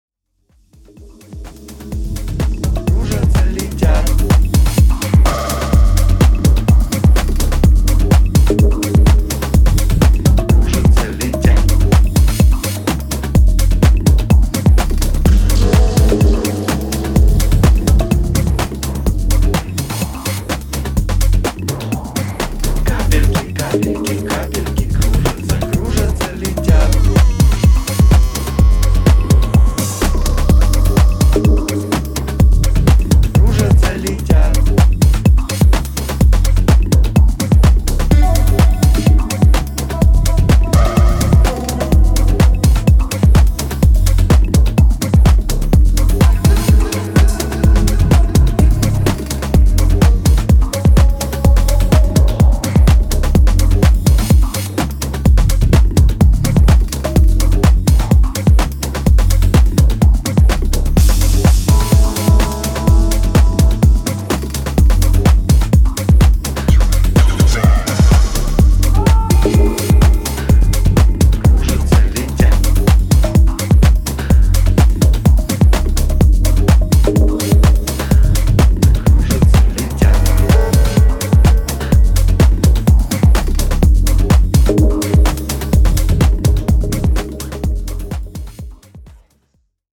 ブロークンなリズムワークを細かなアレンジで繋ぎ止める密かなキラーチューン